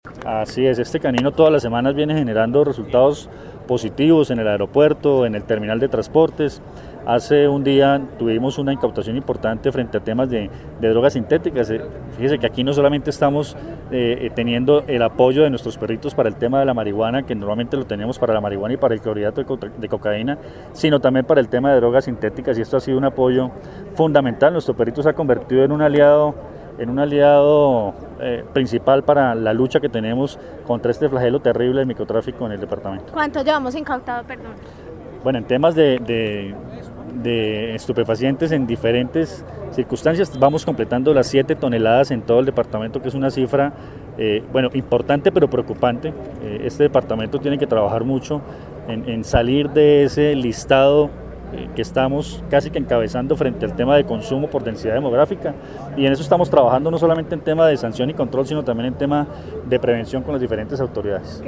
Coronel Luis Fernando Atuesta, comandante Policía, Quindío